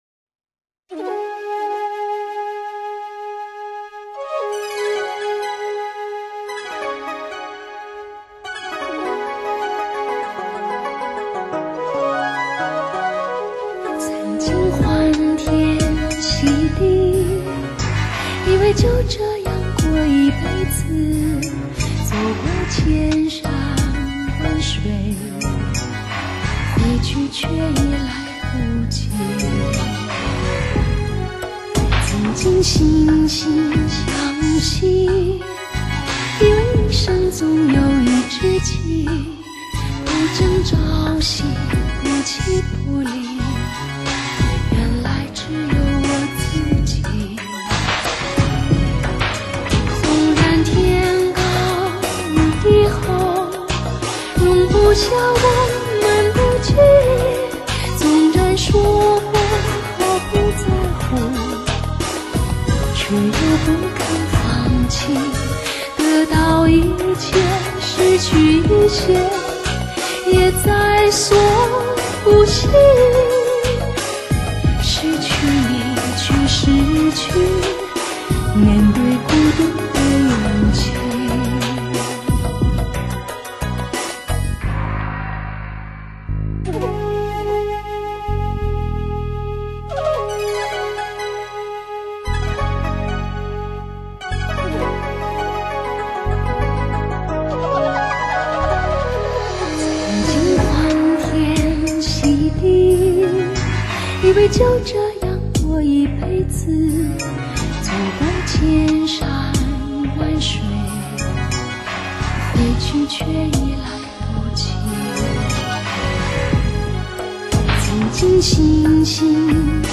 逍遥江湖的味道，尽在此中。